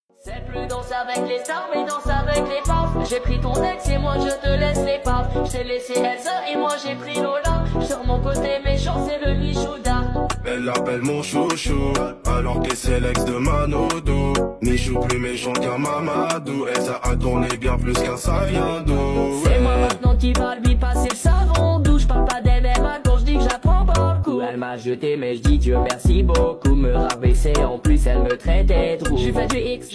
Жанр: Хип-Хоп / Рэп / Альтернатива